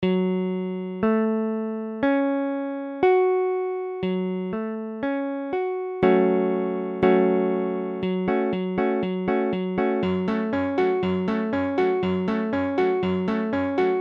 Tablature Gbm.abcGbm : accord de Sol bémol mineur
Mesure : 4/4
Tempo : 1/4=60
A la guitare, on réalise souvent les accords en plaçant la tierce à l'octave.
Sol bémol mineur Barré II (Sol bémol case 2 ré bémol case 4 doigt 3 sol bémol case 4 doigt 4 la case 2 ré bémol case 2 sol bémol case 2)